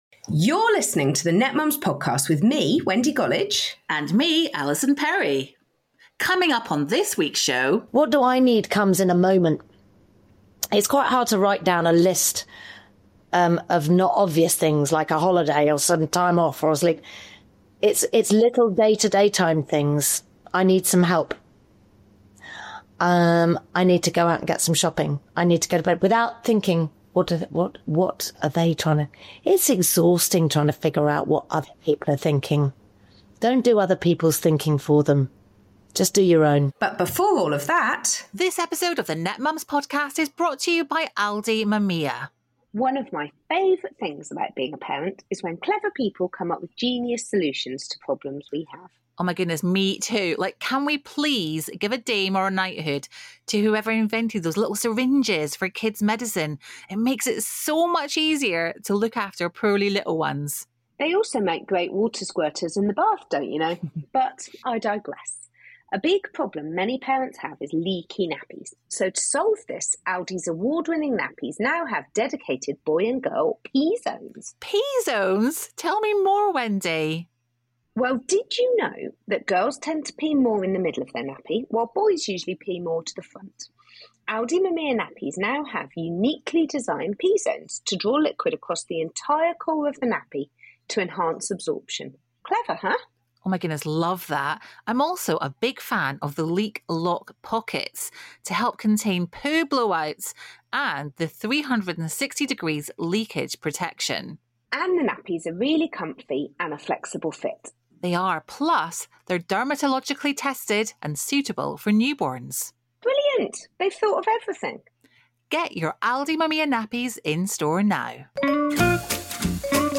Fair warning - this episode gets emotional! The chat gets into the realities of conception, pregnancy, birth, and the often-overlooked postpartum period.